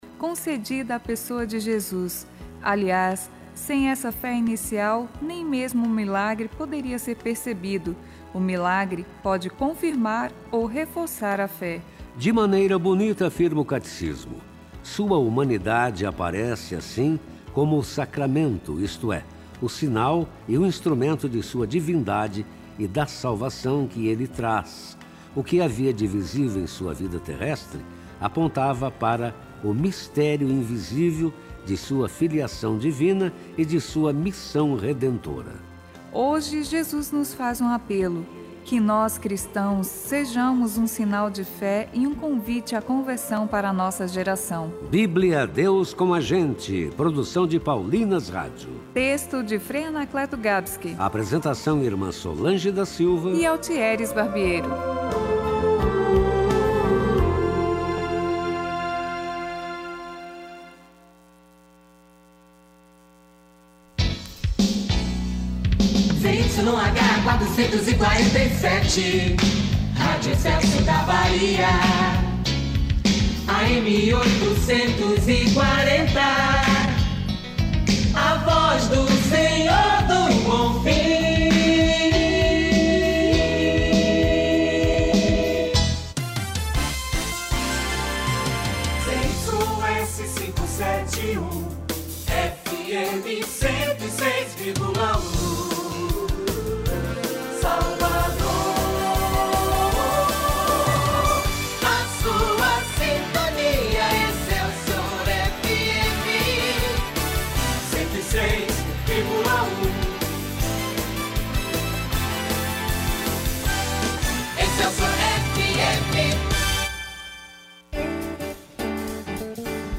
O Saúde no Ar desta quarta-feira, 8 de março, data em que se comemora o Dia Internacional da Mulher, reuniu diferentes mulheres para tratar da luta e das conquistas que permeiam a condição feminina na Bahia.